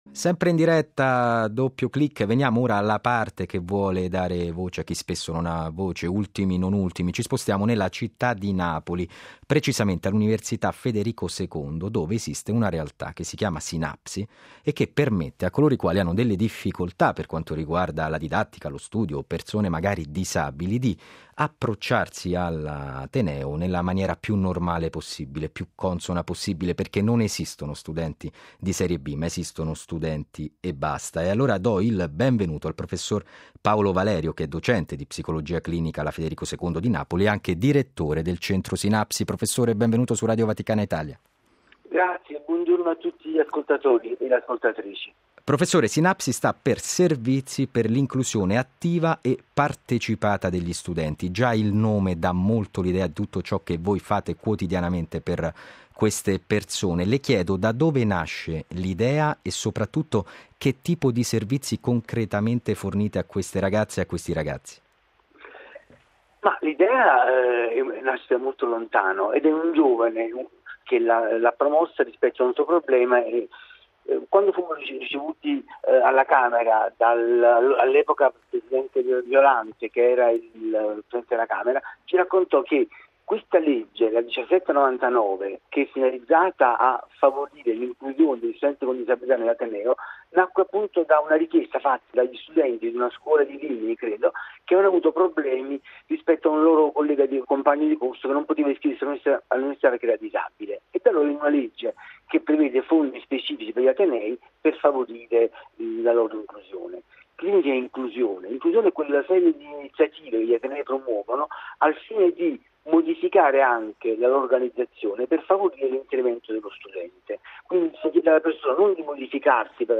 Intervista_Radio_Vaticana.Mp3